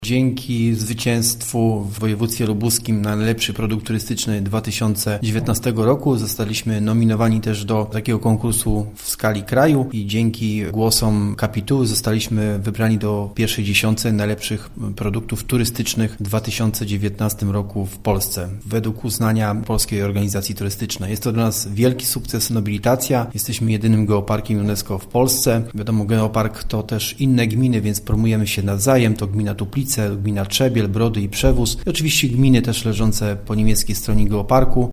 – Organizatorzy targów byli zaskoczeni, że na pograniczu Lubuskiego, Brandenburgii i Saksonii jest taka atrakcja turystyczna – mówi uczestnik targów Piotr Kuliniak, burmistrz Łęknicy.